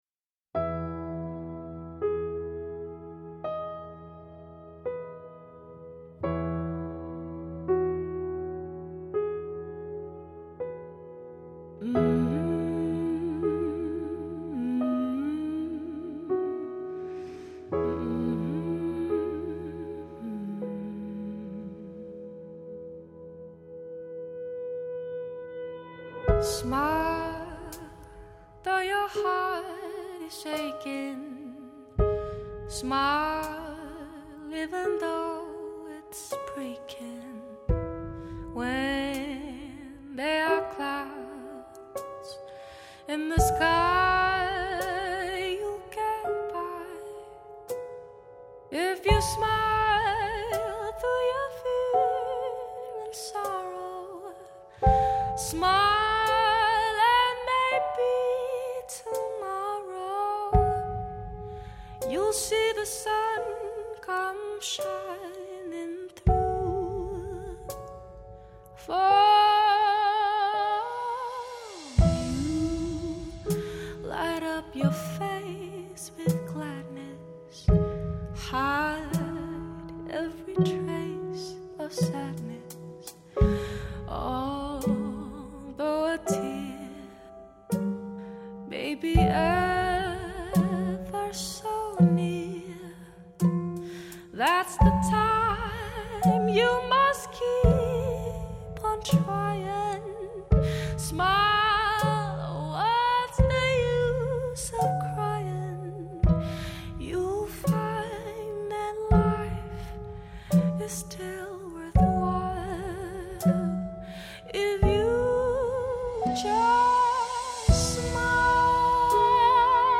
R&B
歌聲甜美多變、展現高超唱功外
通透的女聲、紮實的鼓聲、定位精確的空間感，都讓發燒友欲罷不能，一聽再聽！